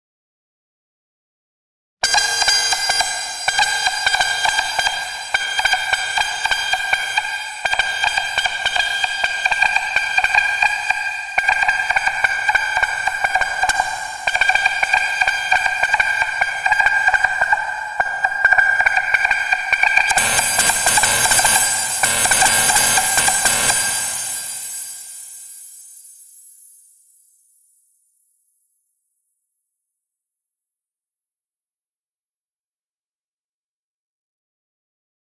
PRINTED AUDIO ... is audio without demand. It’s experimental. It‘s short! It's a headset thing. It ranges from field recordings to noise to 4-track dual mono sounds to whatever comes to mind.